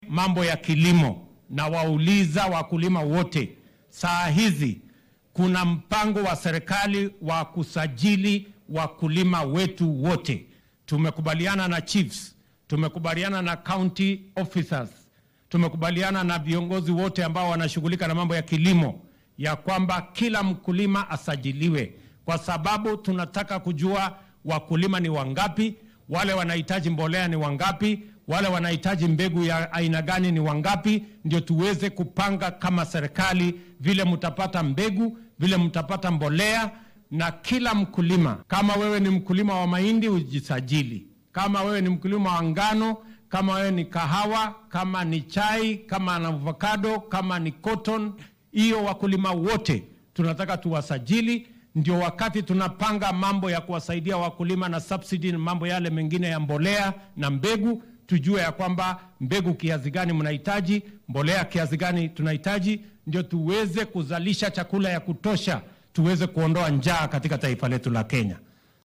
DHAGEYSO:Madaxweynaha dalka oo ka hadlay diiwaangelinta beeralayda
Madaxweynaha dalka William Ruto ayaa xilli uu maanta ku sugnaa ismaamulka Homa Bay waxaa uu soo hadal qaaday qorshaha dowladda ay ku diiwaangelinayso beeralayda.